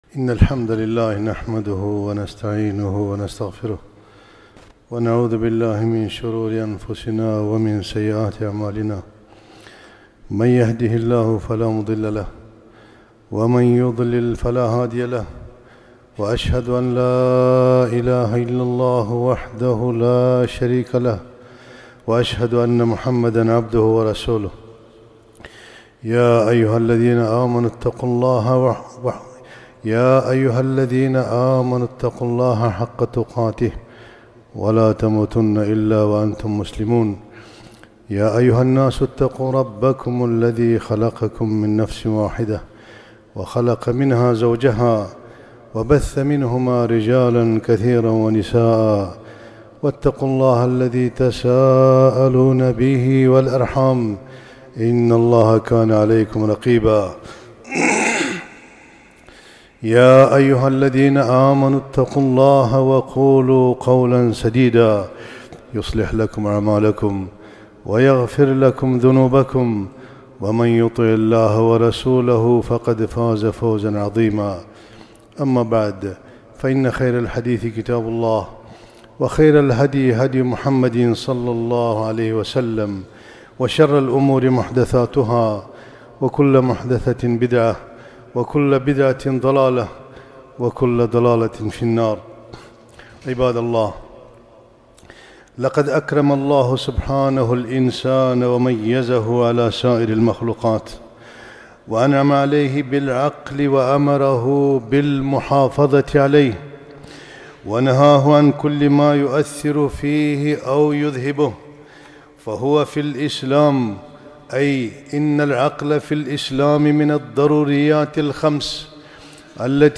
خطبة - التحذير من آفة المخدرات والتذكير بصيام عاشوراء